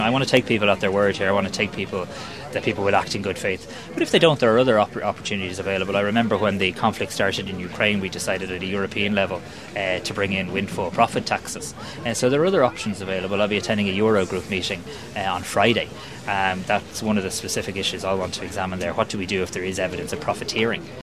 He told reporters the Government has levers available to it if there is non compliance………….